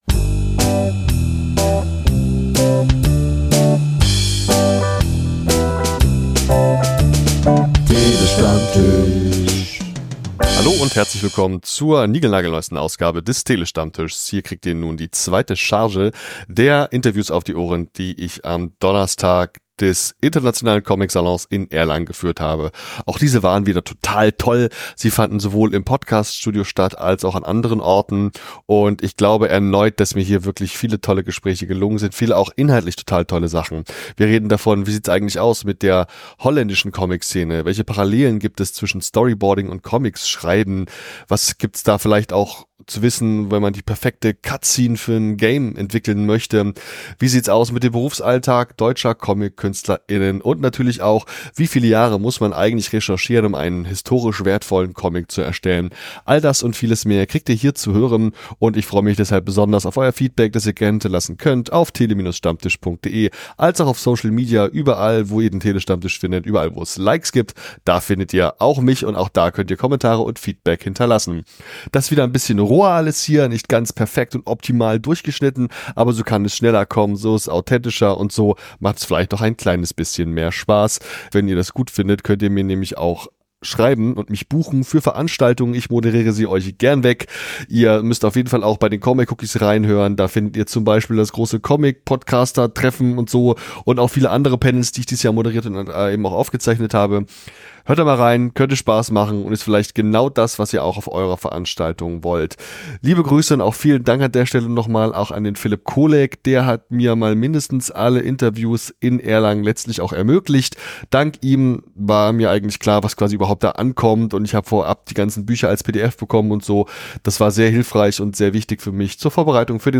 Ich habe vor Ort sehr viel gearbeitet und viele Stunden lang Interviews geführt und aufgezeichnet.